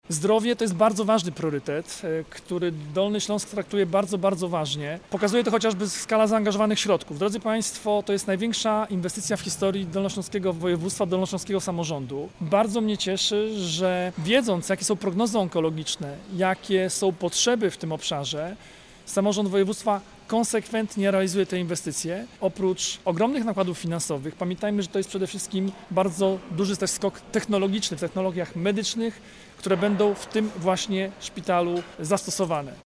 -Zdrowie Dolnoślązaków to priorytet. Traktujemy go bardzo poważnie, zaznacza Jarosław Rabczenko, członek zarządu województwa.